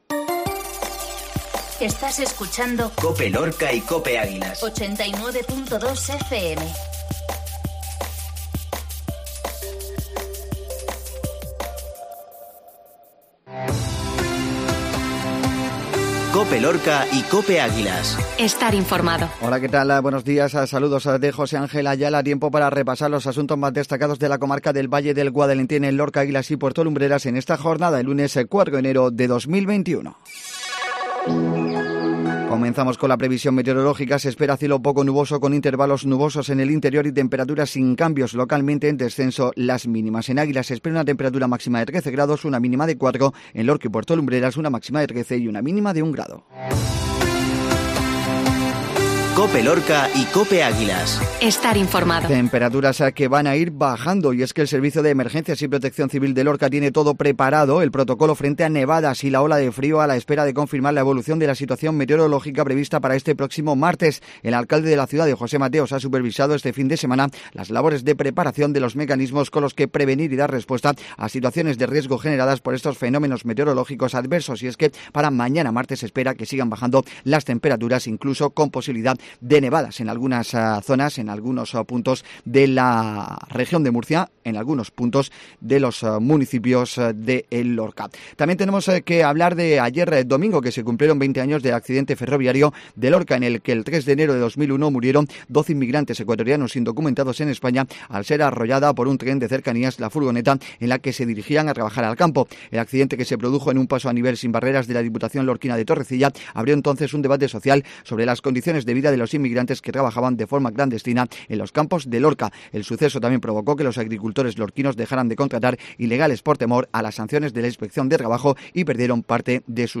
INFORMATIVO MATINAL LUNES